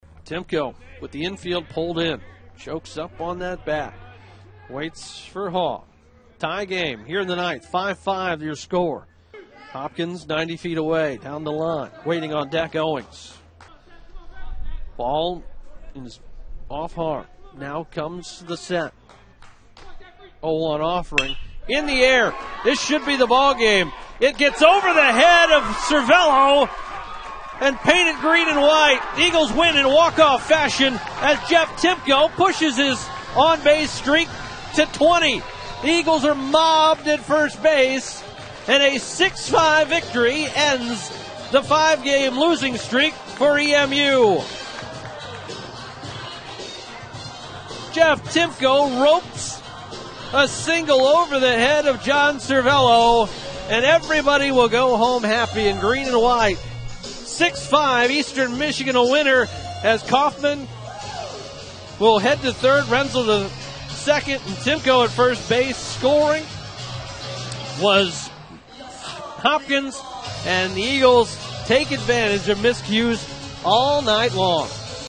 Post Game Audio: